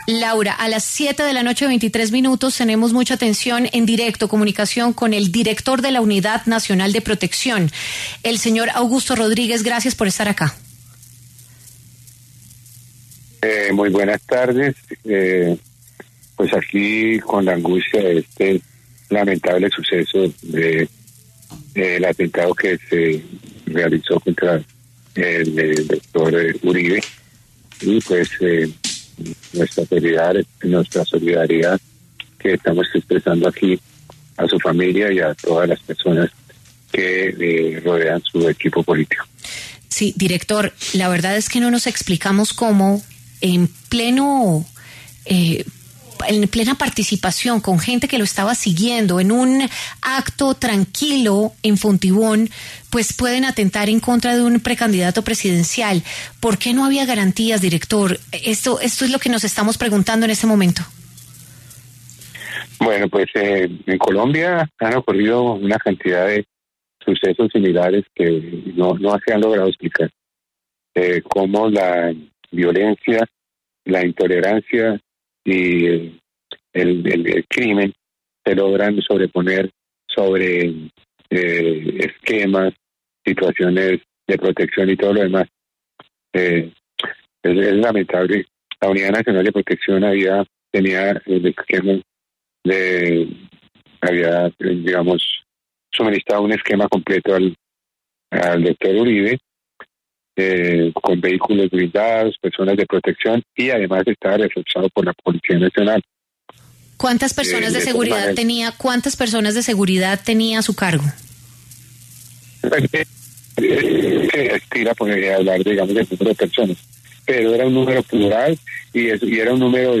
Augusto Rodríguez, director de la Unidad Nacional de Protección, conversó en W Radio sobre las fallas que se registraron en el esquema de seguridad del senador y precandidato presidencial Miguel Uribe, quien fue víctima de un atentado en la localidad de Fontibón, en Bogotá.